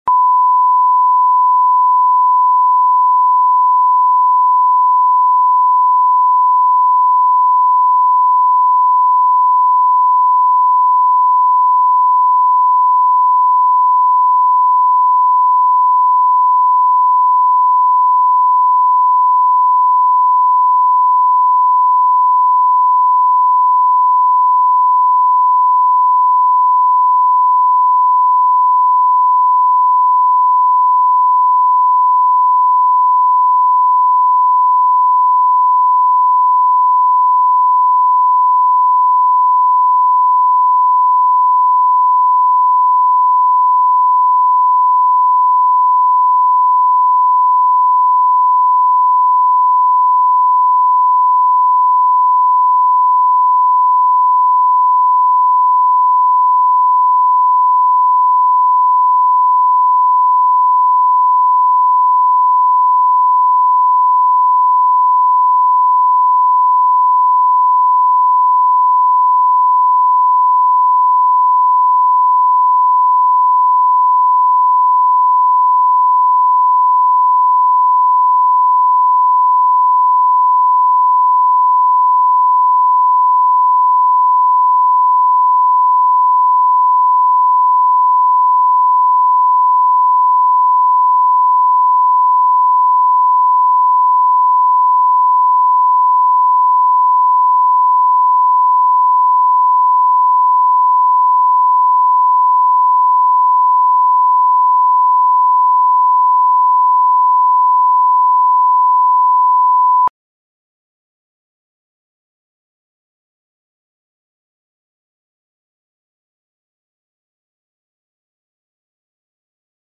Аудиокнига Мудрые женщины | Библиотека аудиокниг
Прослушать и бесплатно скачать фрагмент аудиокниги